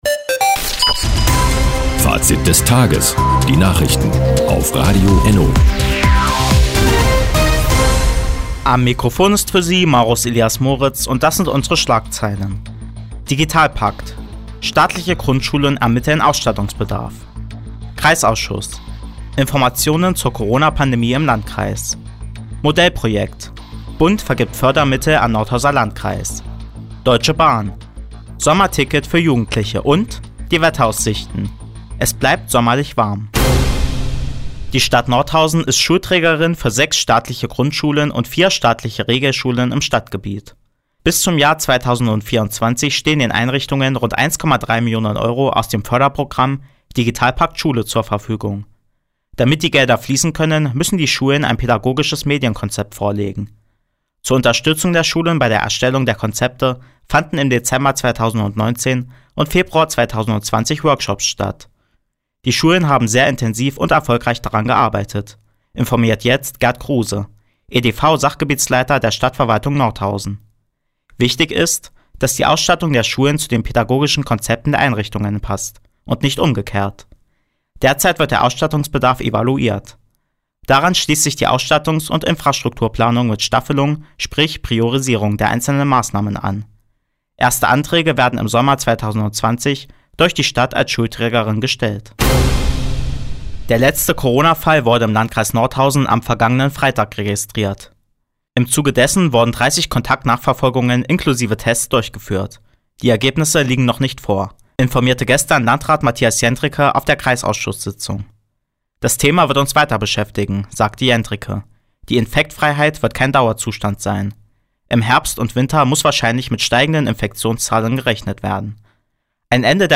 Di, 16:51 Uhr 23.06.2020 Neues von Radio ENNO Fazit des Tages Anzeige symplr (1) Seit Jahren kooperieren die Nordthüringer Online-Zeitungen und das Nordhäuser Bürgerradio ENNO. Die tägliche Nachrichtensendung ist jetzt hier zu hören.